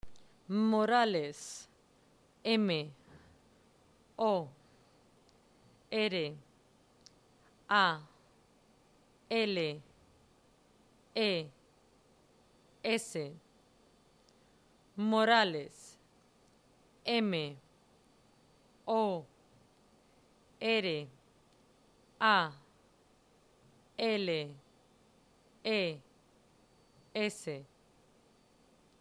EJERCICIO 2: DELETREO
Cada apellido se deletrea dos veces.